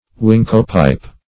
Wincopipe \Win"co*pipe\, n. (Bot.)